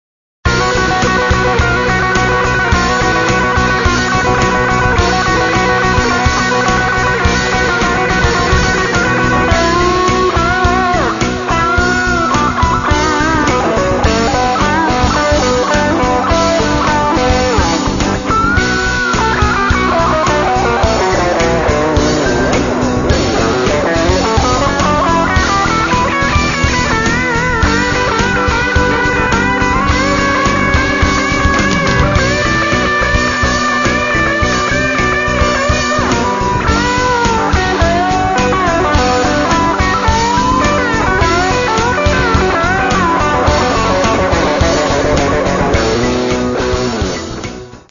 Каталог -> Рок та альтернатива -> Готика